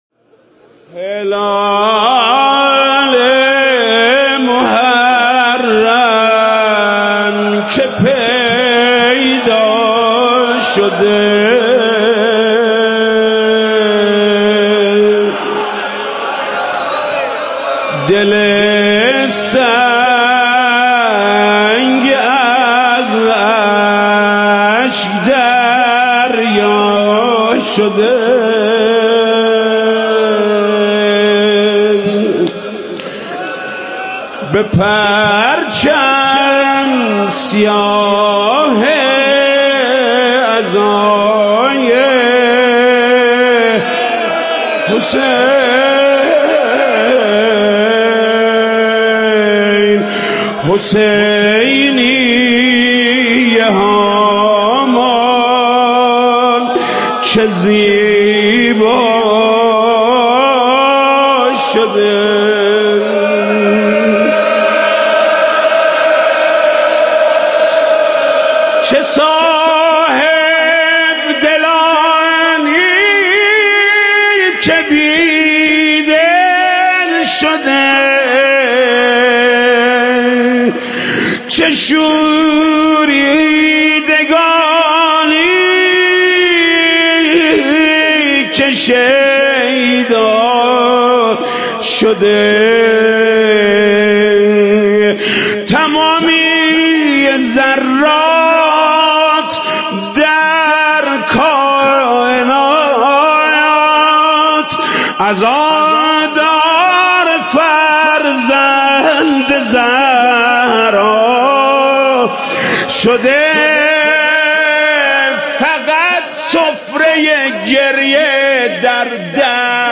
روضه شب اول محرم با صدای محمود کریمی -( هلال محرم که پیدا شده )